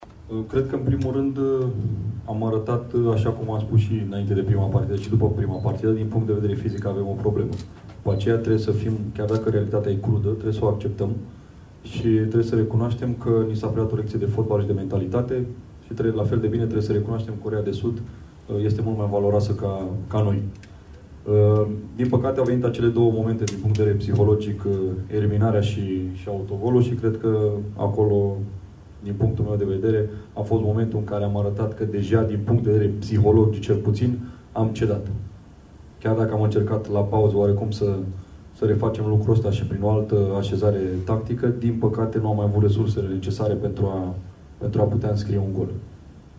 Antrenorul Mirel Rădoi recunoaște că sunt probleme fizice cu jucătorii noștri și că adversara a fost net superioară: